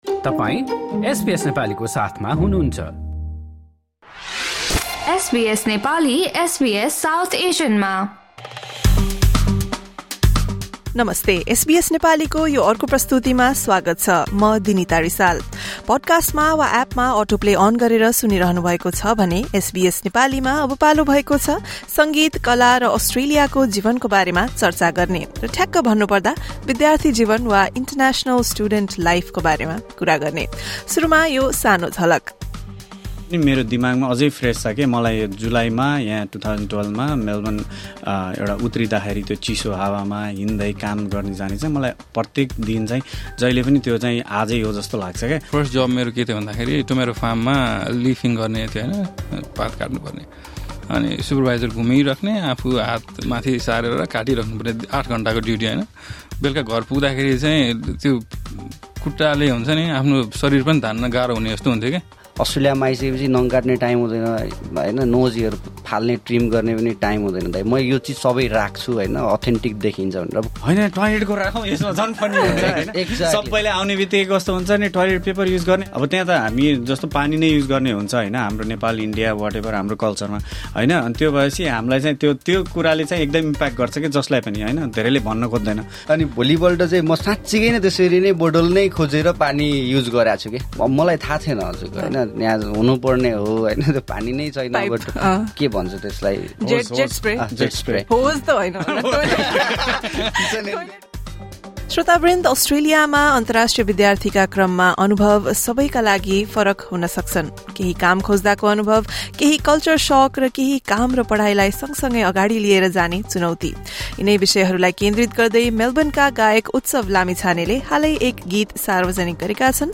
at SBS Studios Melbourne